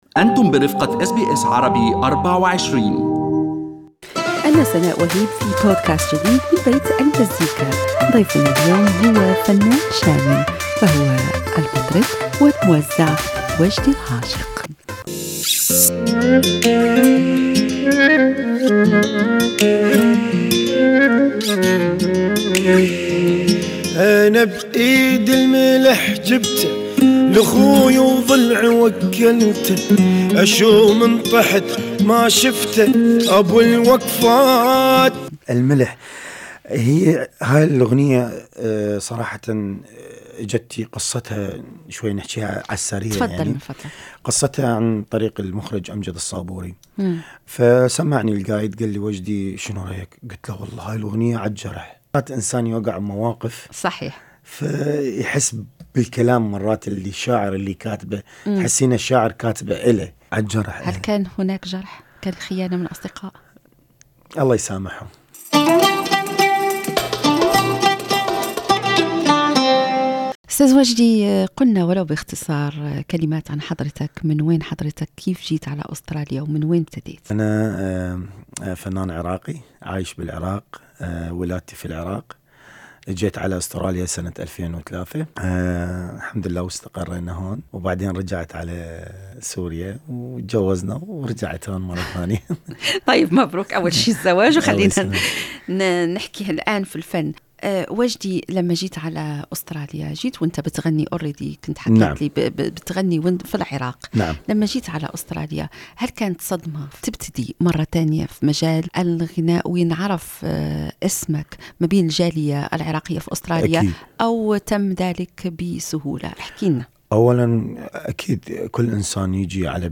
يستضيف بيت المزيكا في حلقته الأسبوعية المطرب العراقي